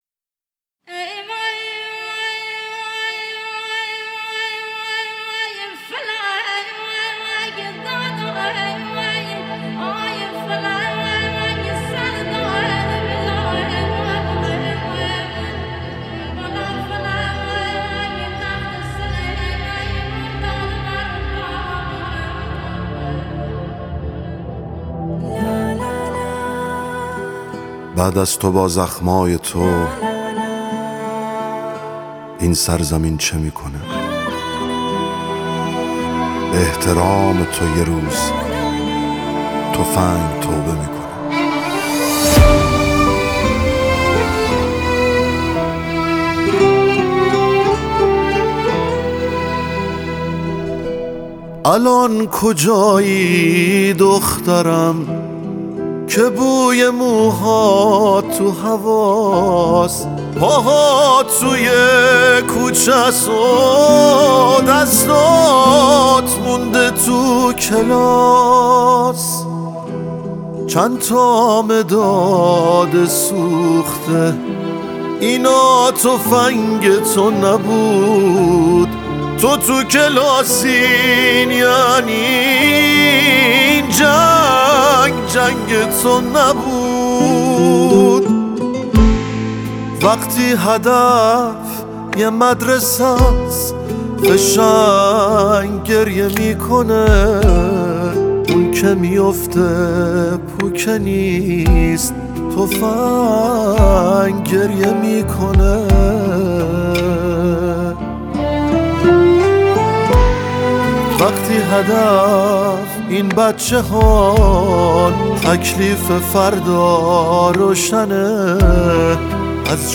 نوازنده کمانچه
نوازنده گیتار
نوازند ویولن و ویولا